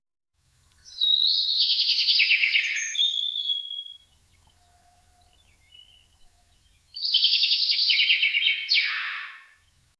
vogelgezwitscher-leichtes-nknzybf4.wav